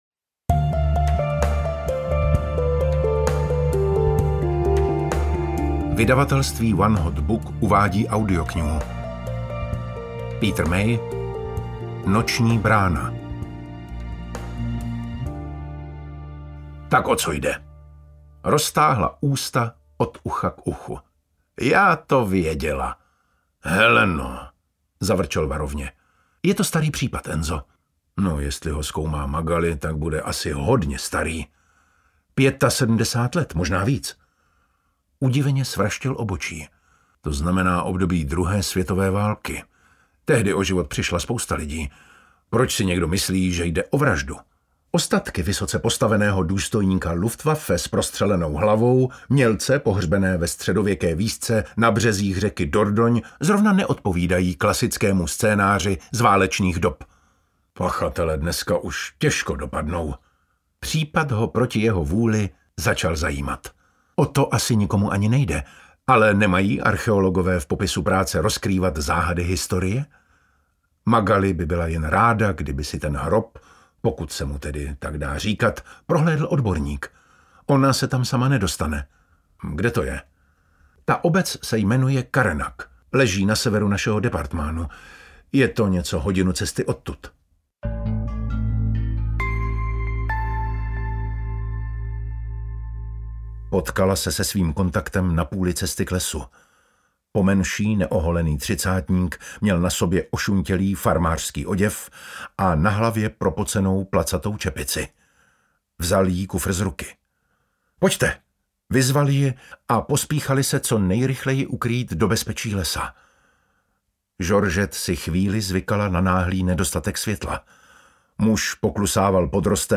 Noční brána audiokniha
Ukázka z knihy